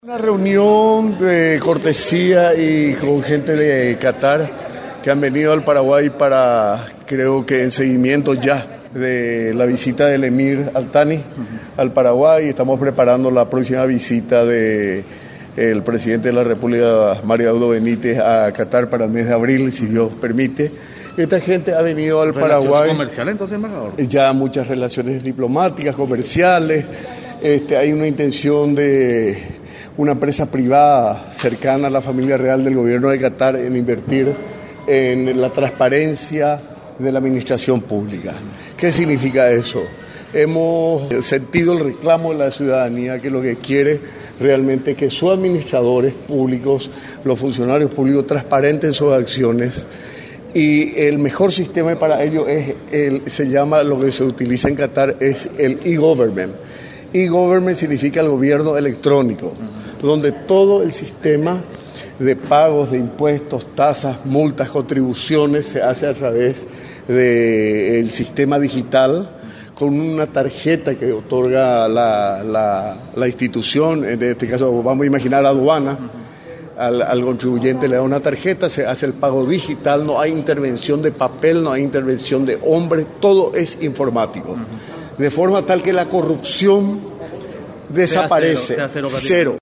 El embajador paraguayo en Qatar Ángel Barchini, afirmó que podría implementarse el Gobierno Electrónico, un sistema de alta tecnología para pago de impuestos utilizado en aquel país.